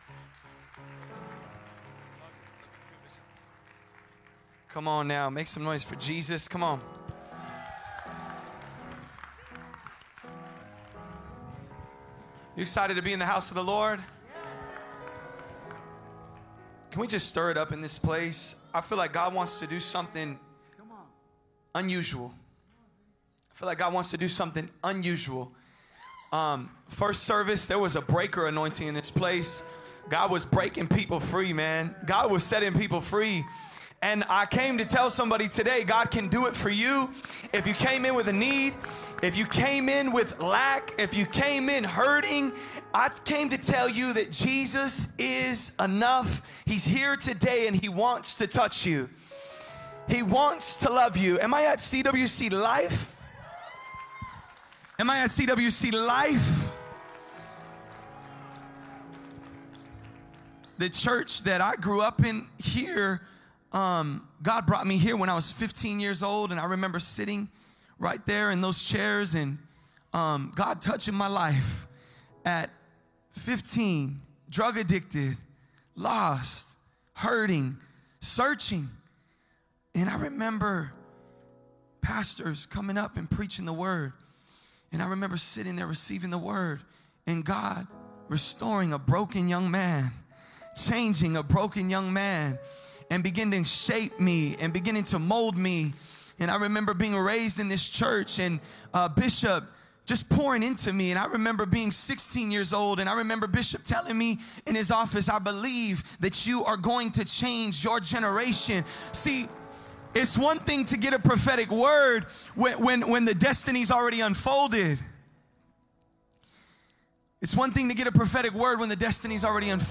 Sermons | CWC LIFE Manteca CA